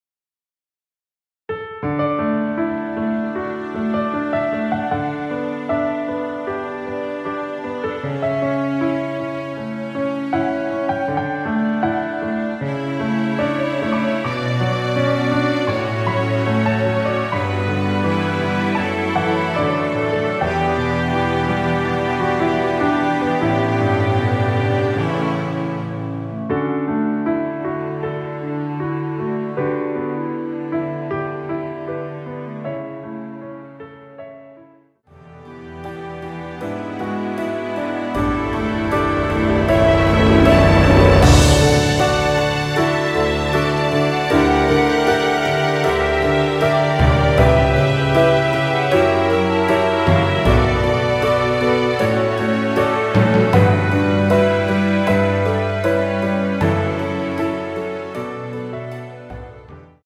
원키에서(-7)내린 멜로디 포함된 MR입니다.
앞부분30초, 뒷부분30초씩 편집해서 올려 드리고 있습니다.
중간에 음이 끈어지고 다시 나오는 이유는